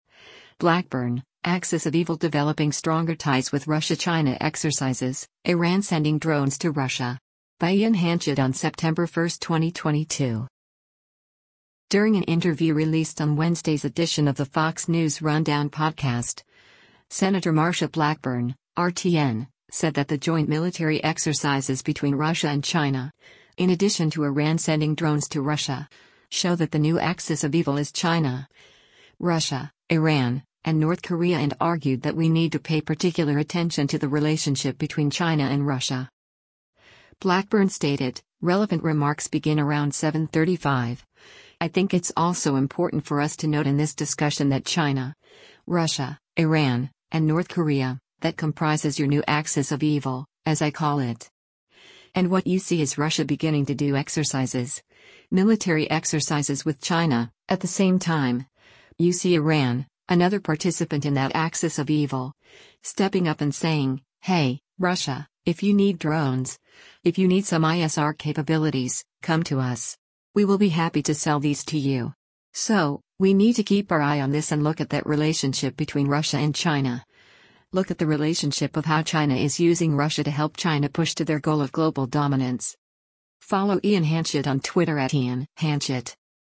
During an interview released on Wednesday’s edition of the “Fox News Rundown” podcast, Sen. Marsha Blackburn (R-TN) said that the joint military exercises between Russia and China, in addition to Iran sending drones to Russia, show that the “new axis of evil” is China, Russia, Iran, and North Korea and argued that we need to pay particular attention to the relationship between China and Russia.